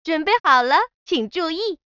ready_china.wav